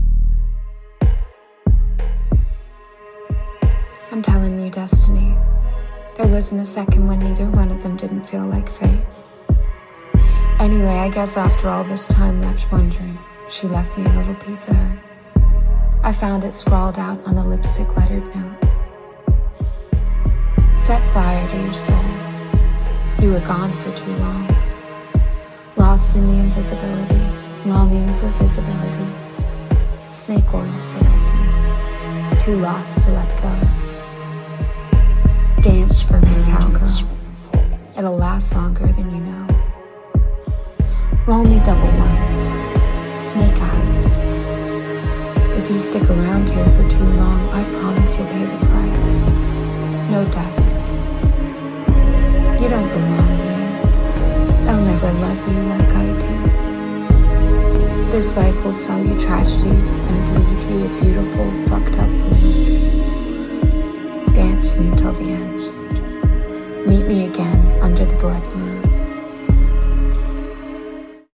20/30's Neutral/RP,
Compelling/Cool/Assured
LOVE FOR SALE, Conditions Apply (US accent)